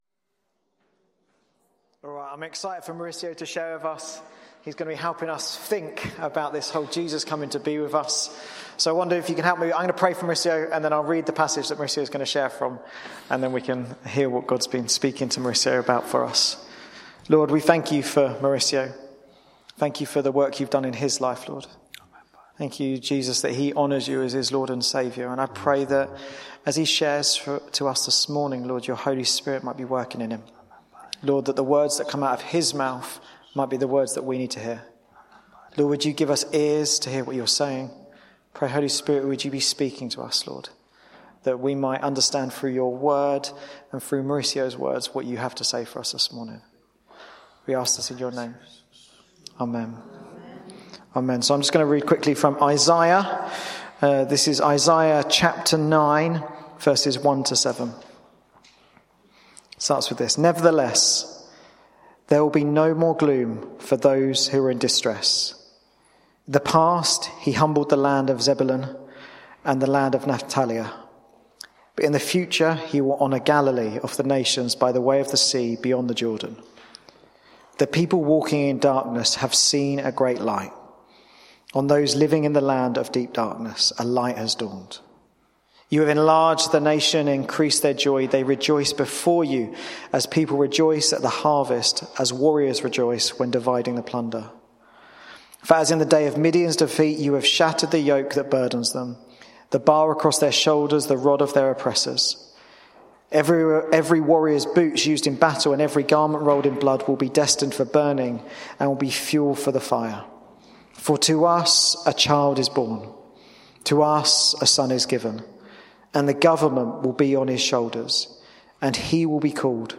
The Mighty Councellor, Bassett Street Sermons